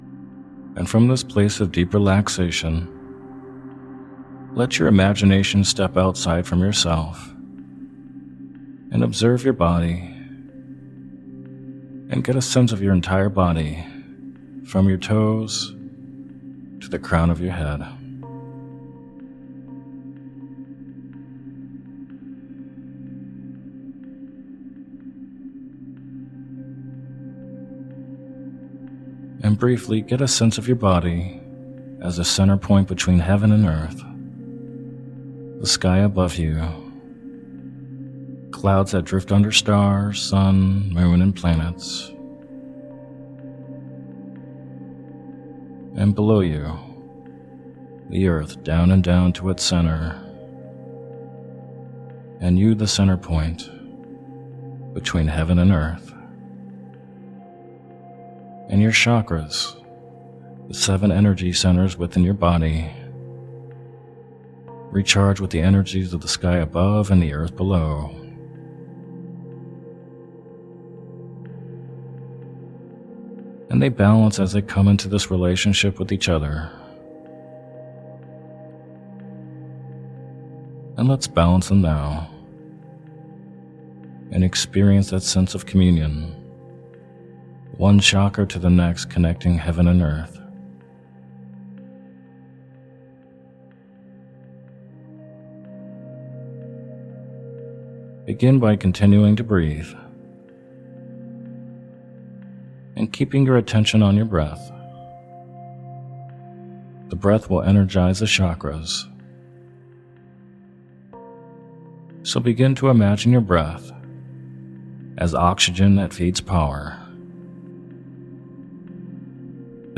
In this meditation or sleep hypnosis session, you will be guided to clear all of your 7 chakras.